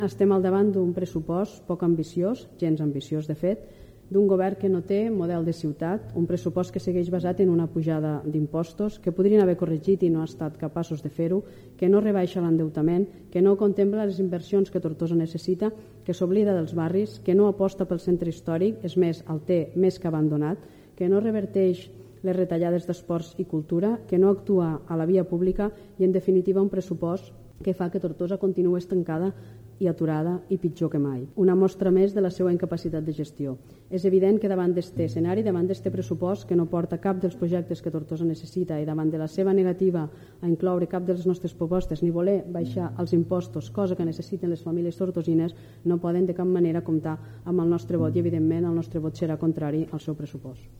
El Govern de Movem-PSC i ERC ha aprovat amb el suport de la CUP el pressupost per al 2025, en un ple extraordinari celebrat aquest divendres.